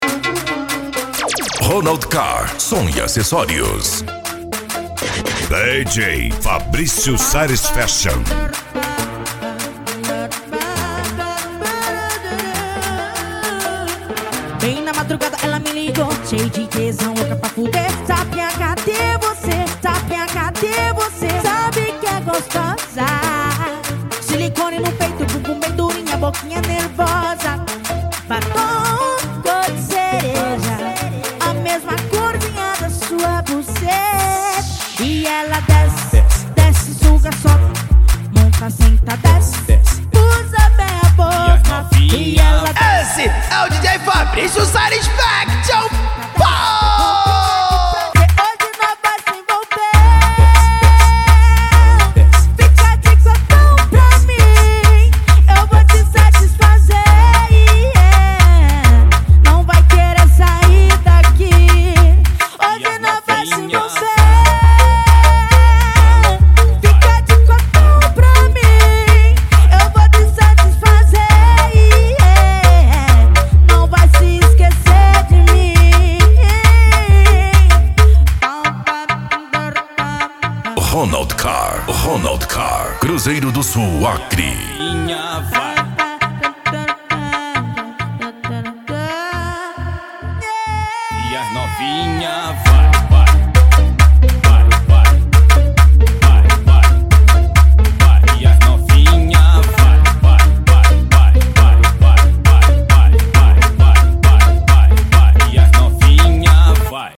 Bass
Deep House
Euro Dance